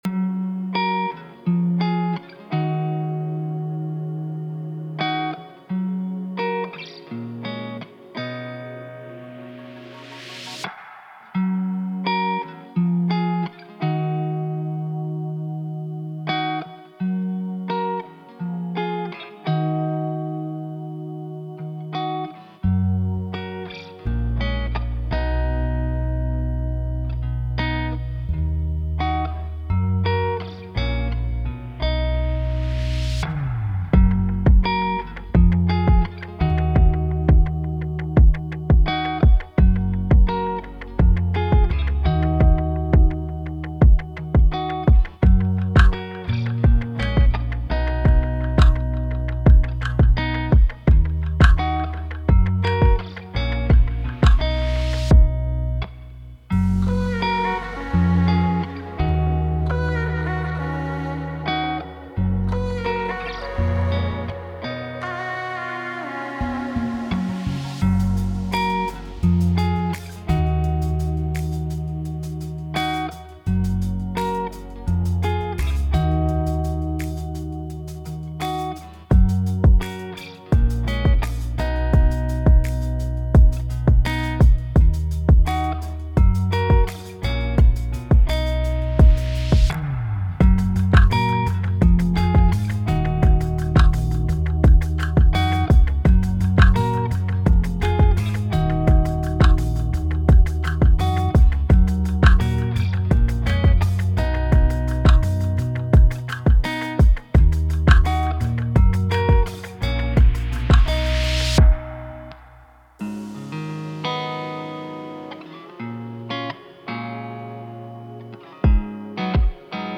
Minus One